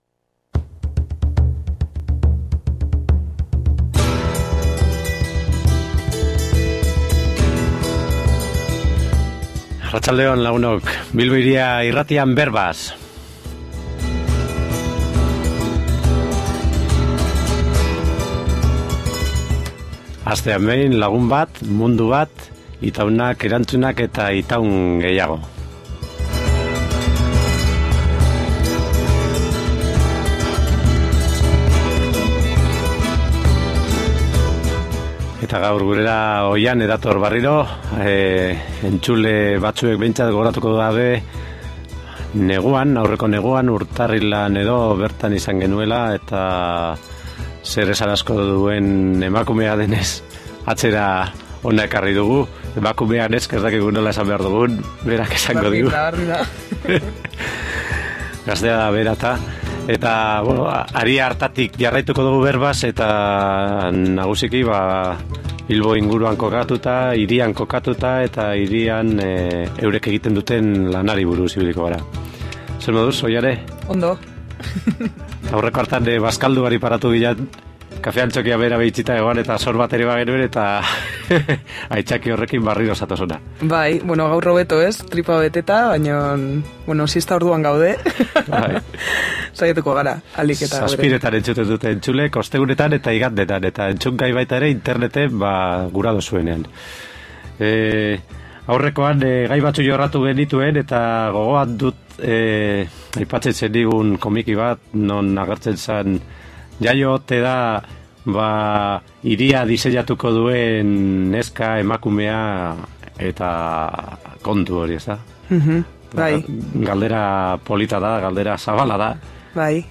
Duela hilabete batzuetako elkarrizketa oparoari jarraitu diogu, zeresan handia duelakoan.